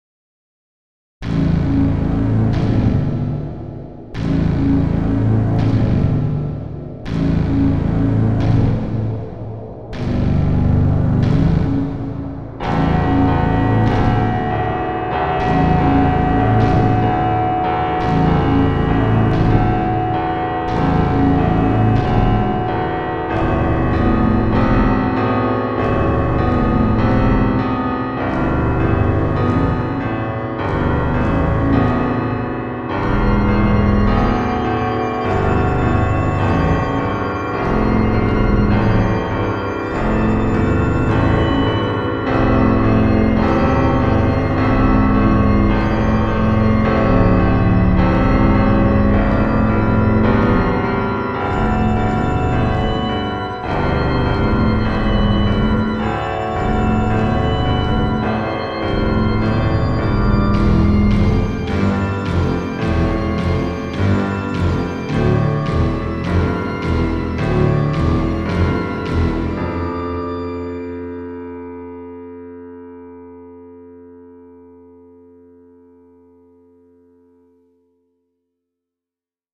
First piano music composition "The Bitter End" inspired by Akira Yamoka's music An emotionally distressed suicidal woman on the brink of insanity.